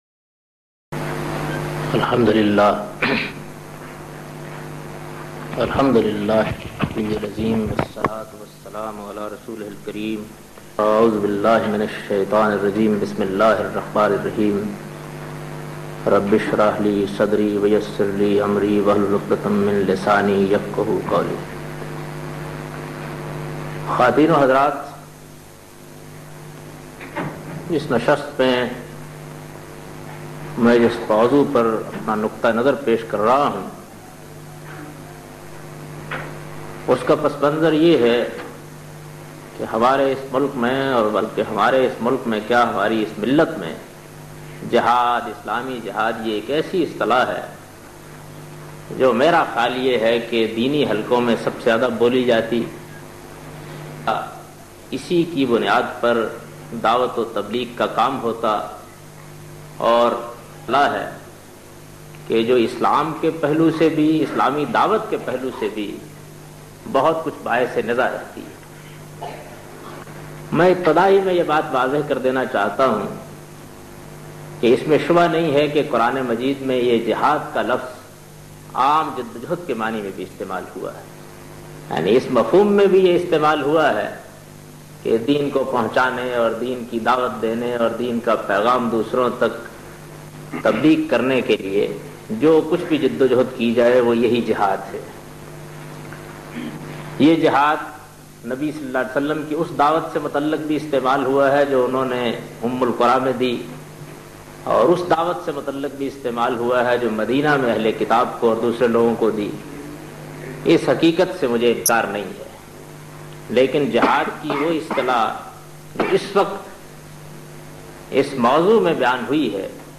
In this video Javed Ahmad Ghamidi speaks about the concept of "The Holy War of Islam". A lecture delivered in 1991.